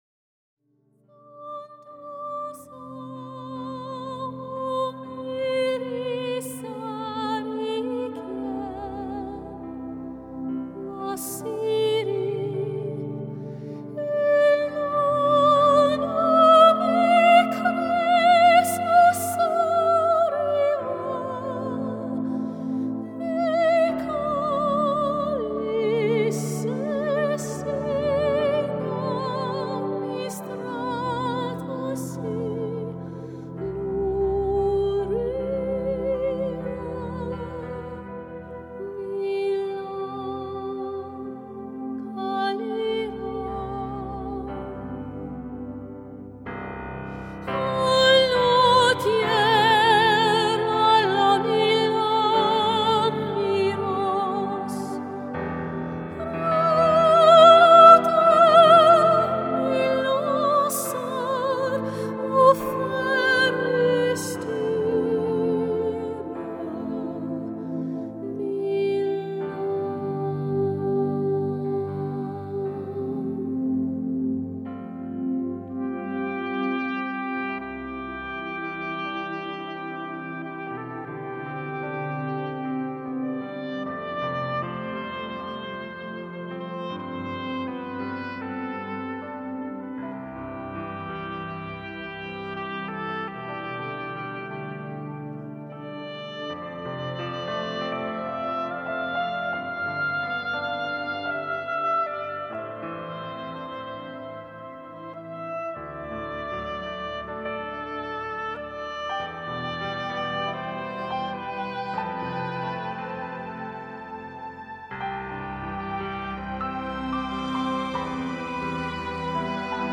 soprano.